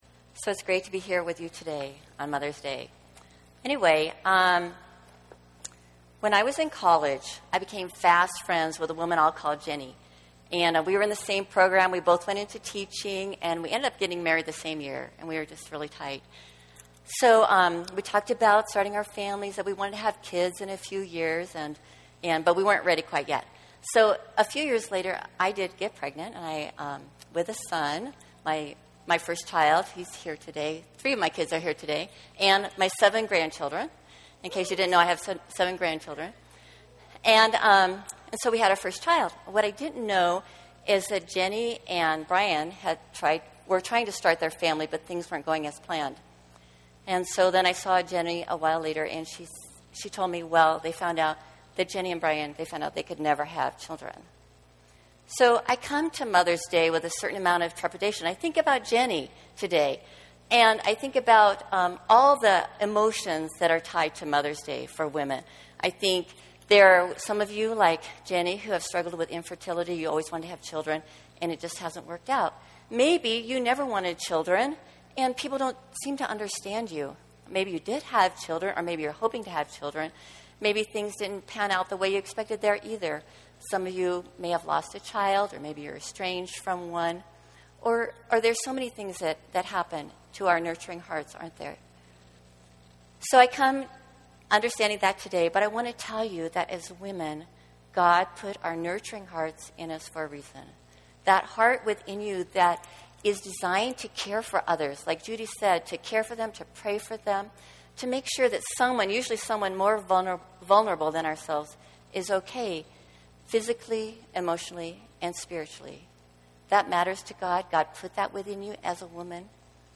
If you would like to hear a recording of me speaking, here is my Mother ‘s Day talk “A Woman and Her God,” given at my church a few years ago.